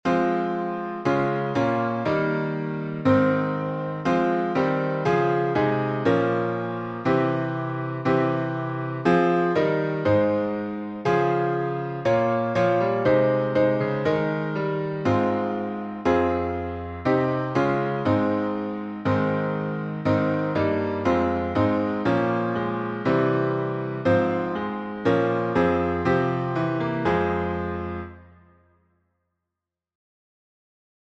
Key signature: A flat major (4 flats)